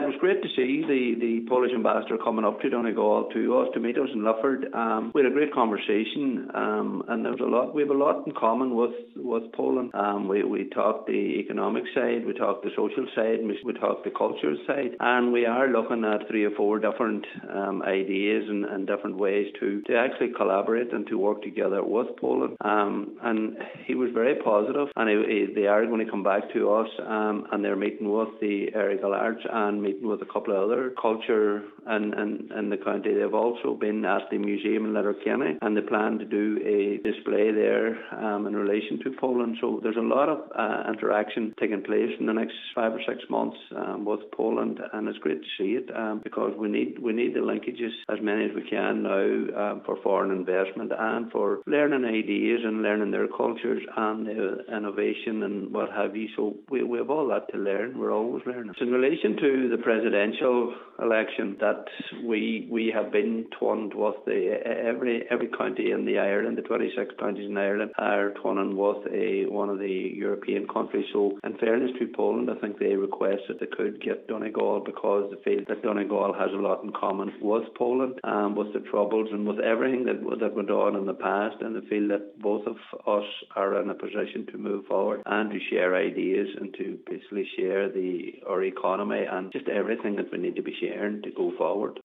Cathaoirleach, Cllr Paul Canning highlights the relationship that is building: